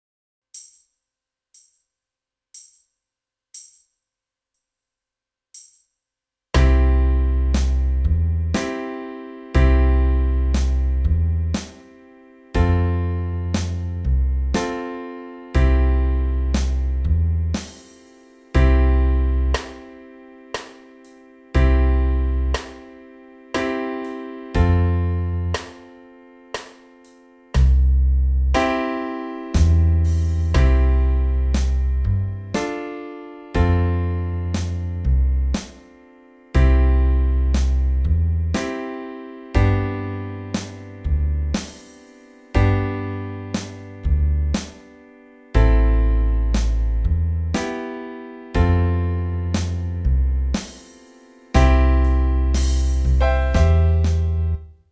48 Songs from Ireland & Great Britain für die Tremolo / Oktav Mundharmonika
Die MP3- Sounds (Melodie und Band sowie Band alleine – Playalong) sind in einem Player mit regelbarer Abspielgeschwindigkeit online verfügbar.
eileen-aroon-band.mp3